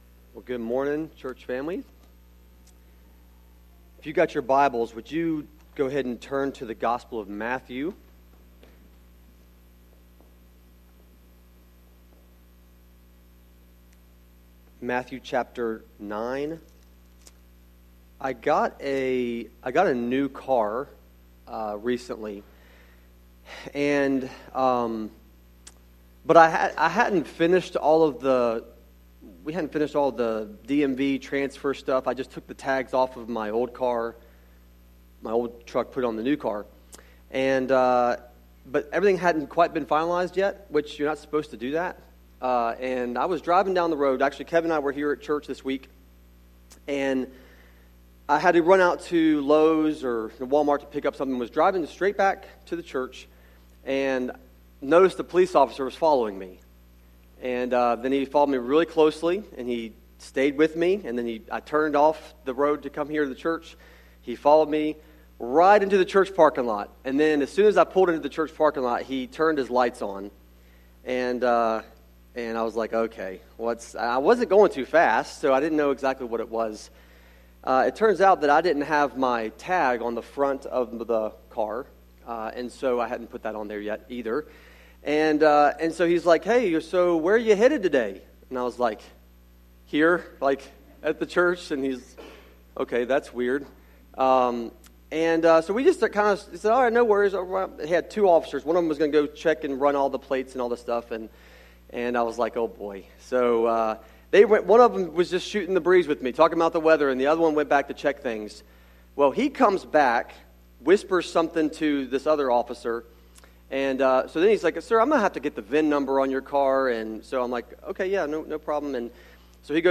sermon-audio-trimmed-2.mp3